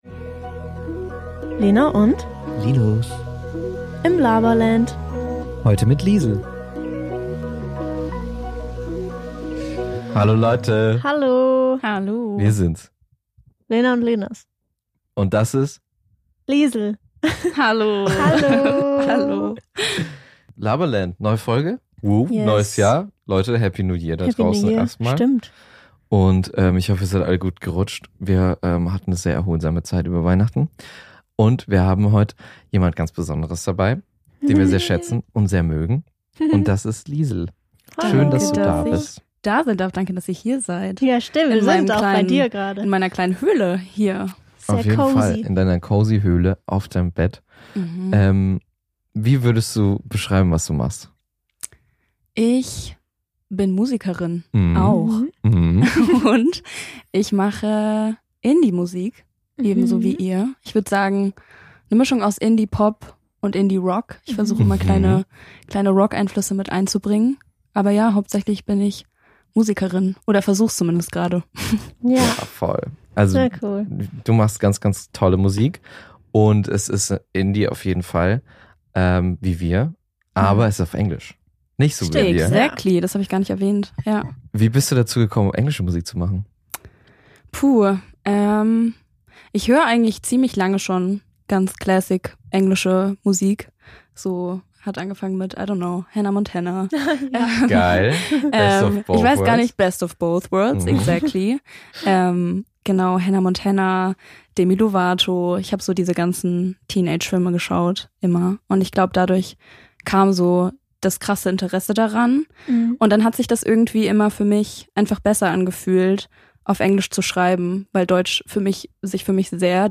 Mit einer Tasse Tee in der Hand quatschen wir über Camp Rock, unsere Neujahrsvorsätze und wie es eigentlich so ist als Vorband zu spielen. Eine sehr vertraute Runde – perfekt für einen entspannten Start ins Jahr.